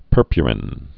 (pûrpyə-rĭn)